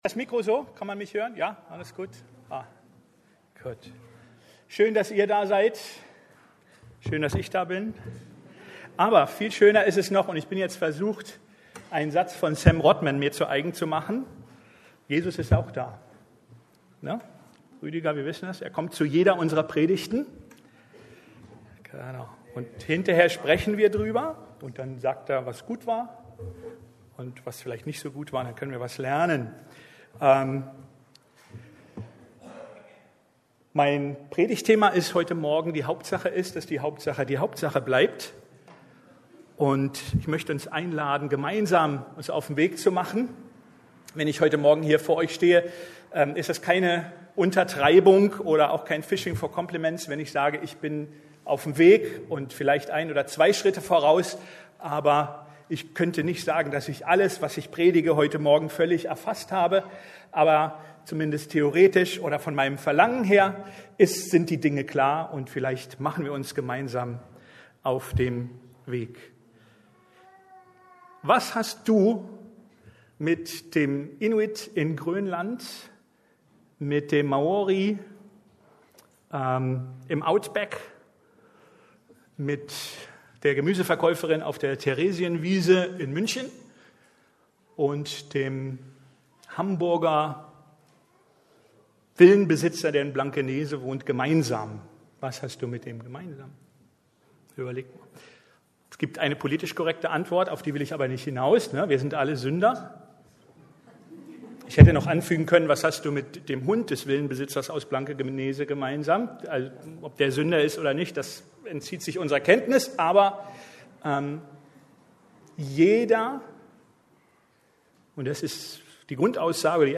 Die Hauptsache ist, dass die Hauptsache die Hauptsache bleibt ~ Predigten der LUKAS GEMEINDE Podcast